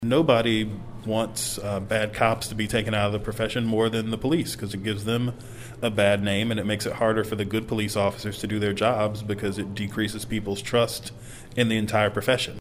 Dogan explained to fellow lawmakers that after the Chauvin killing of George Floyd, it was more important to have clear guidelines in Missouri.